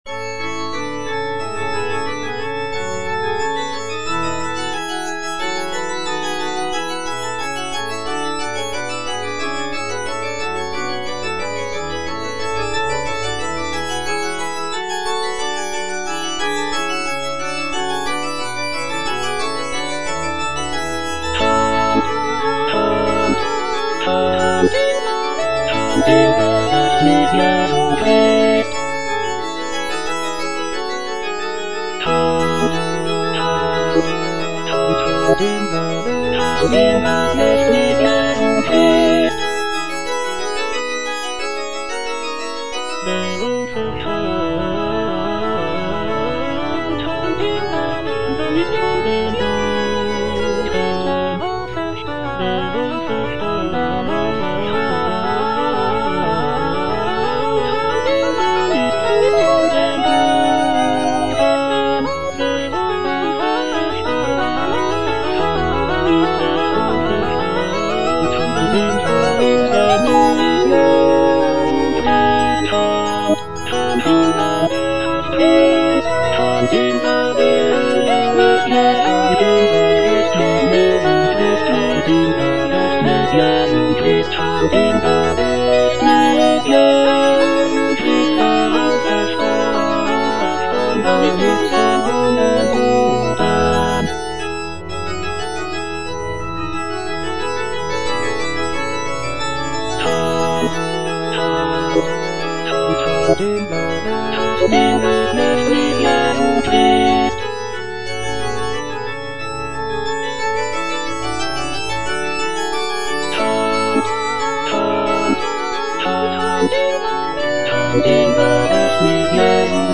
Choralplayer playing Cantata
It features a combination of choruses, arias, and recitatives that reflect on the birth of Jesus Christ and the joy of the Christmas season. The cantata is known for its intricate vocal and instrumental writing, as well as its rich harmonies and expressive melodies.